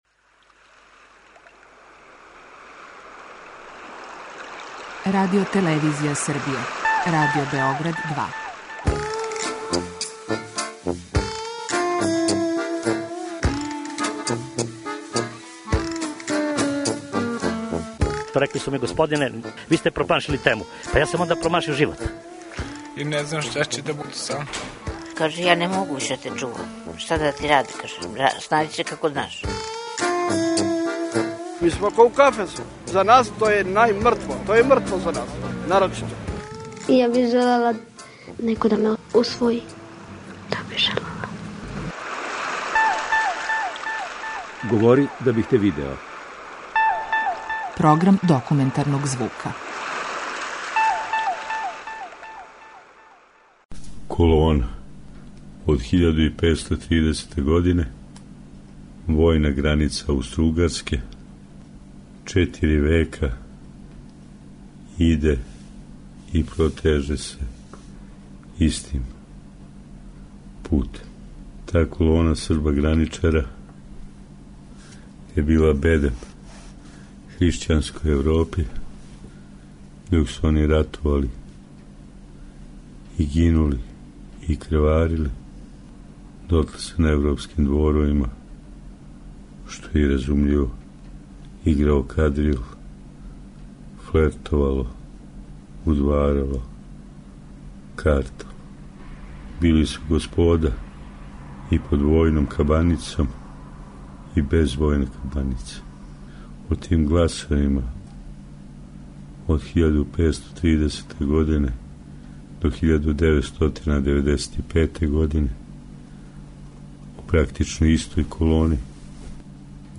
Документарни програм